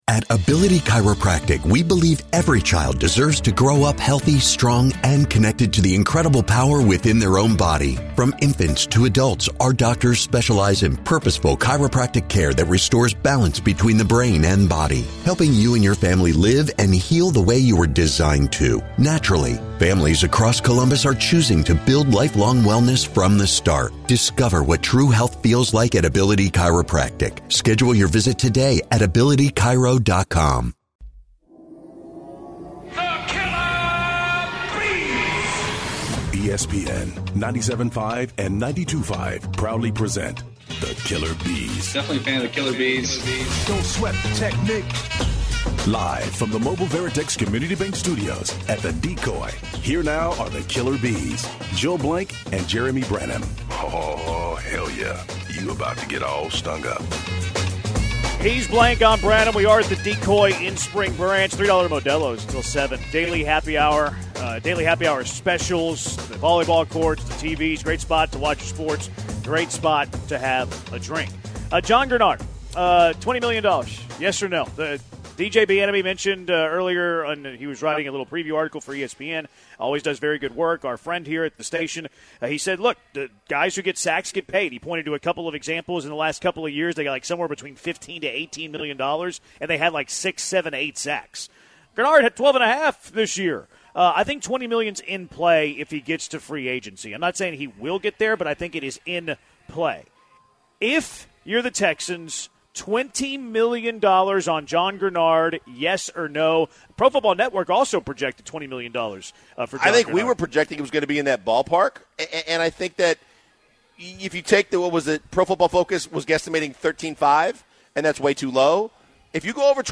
In the last hour of The Killer B's live from The Decoy: